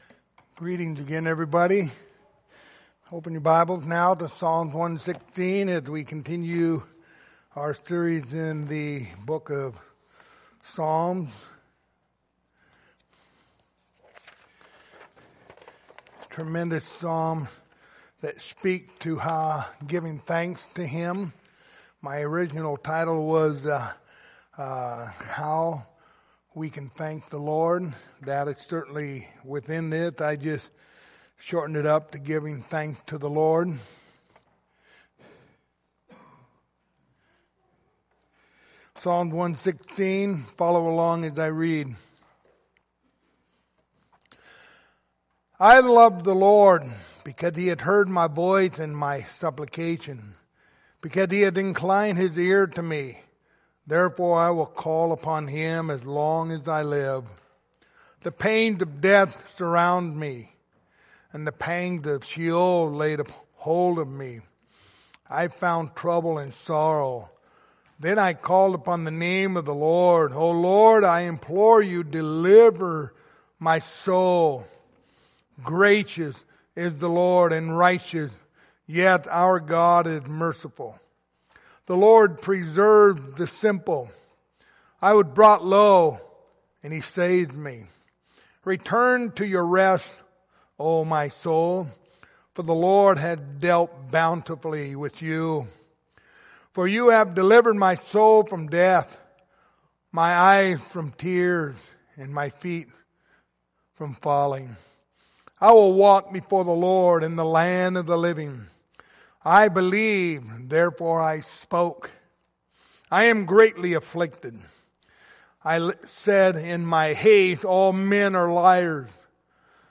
The book of Psalms Passage: Psalms 116:1-19 Service Type: Sunday Evening Topics